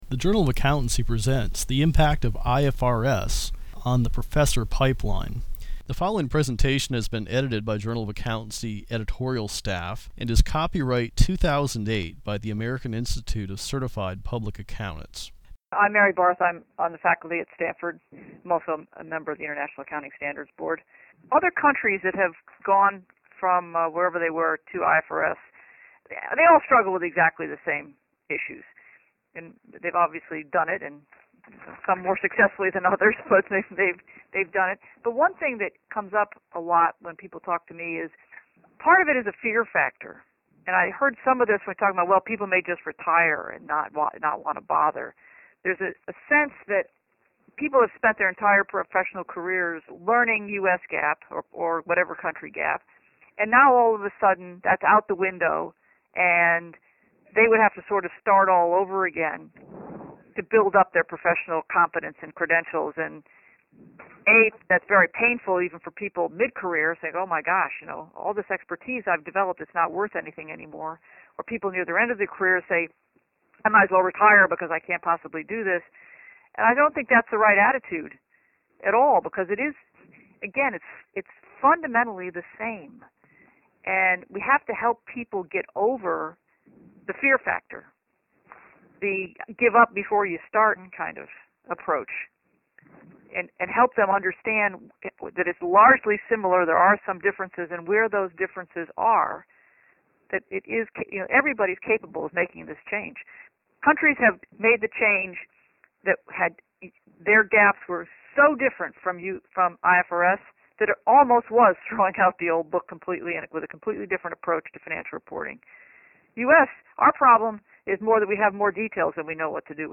To get a sense of the effect IFRS is having at colleges and universities, the JofA hosted a virtual round table, gathering eight accounting professors from around the country by conference call (for a detailed list of panelists, see below).
Here we present audio clips from the round-table discussion, which is detailed in the December issue of the JofA: